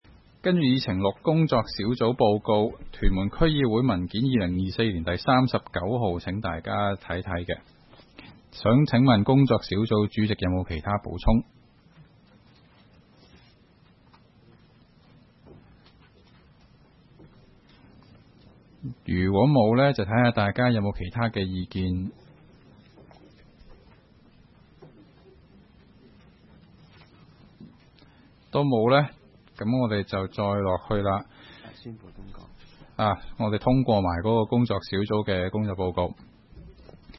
區議會大會的錄音記錄
屯門區議會會議室